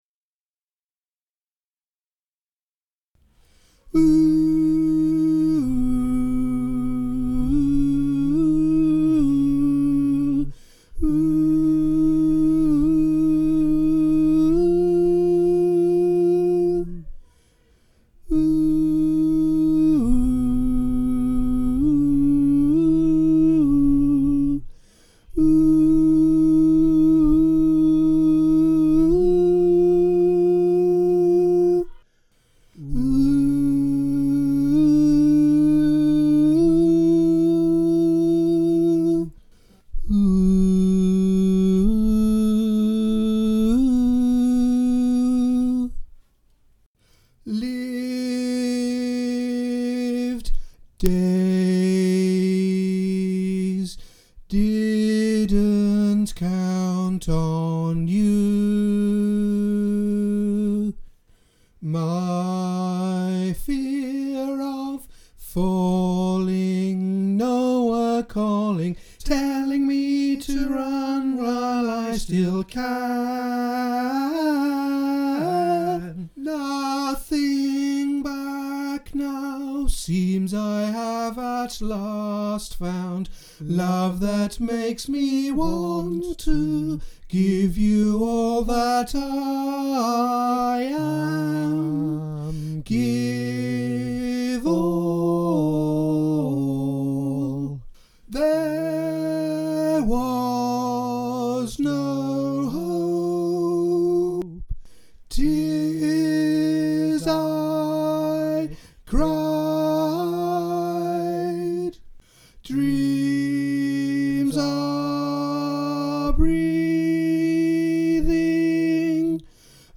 Fear-Of-Falling-Bass.mp3